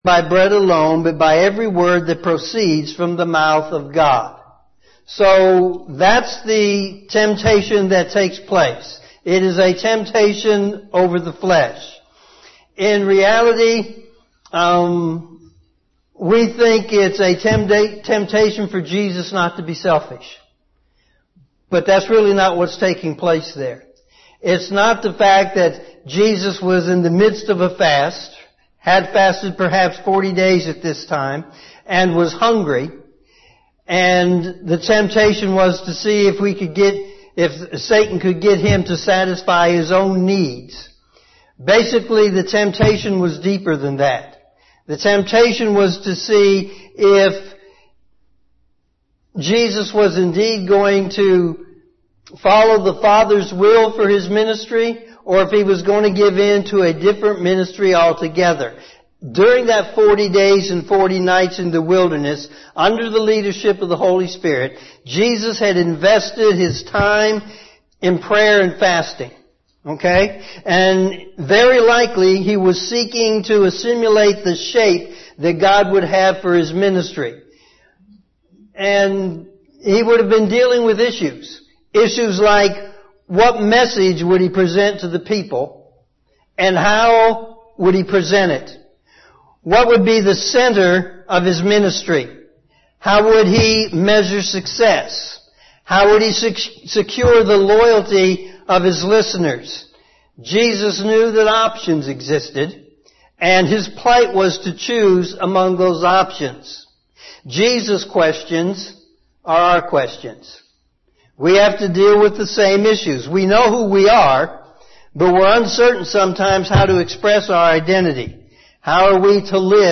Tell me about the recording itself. We sincerely apologize for the first few minutes of this sermon not being recorded.